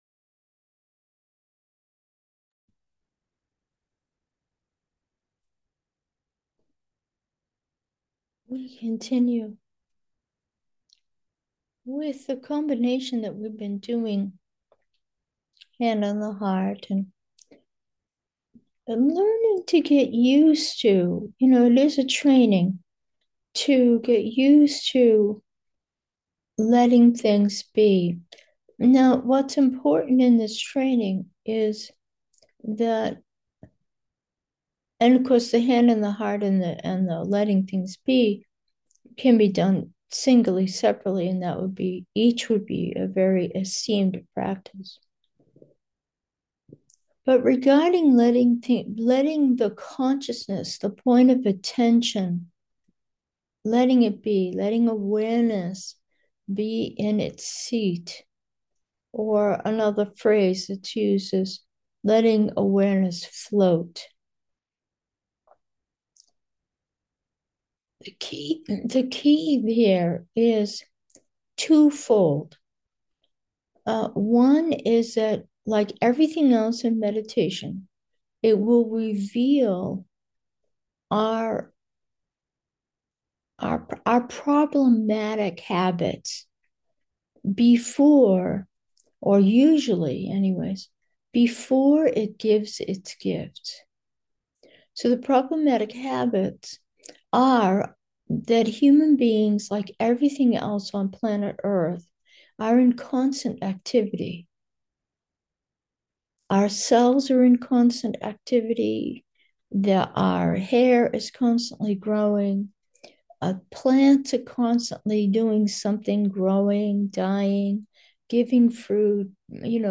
Meditation: the miracle of being